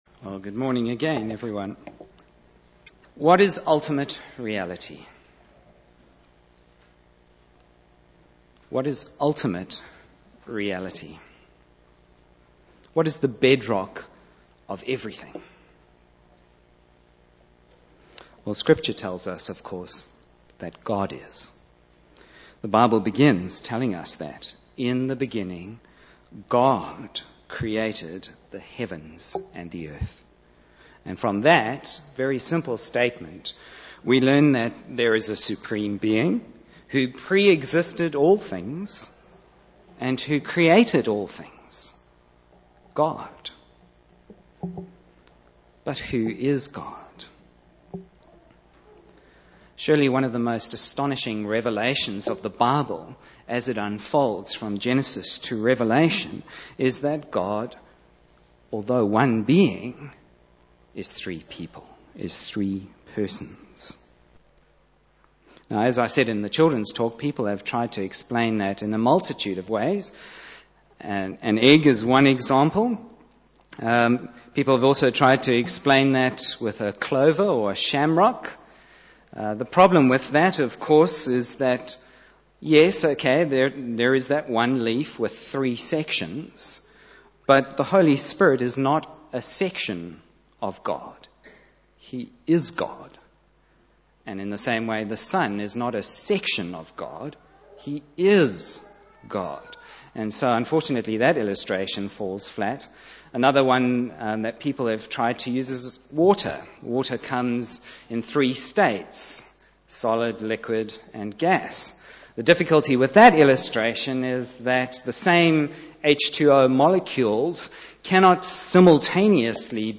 Bible Text: 2 Corinthians 13:11-14 | Preacher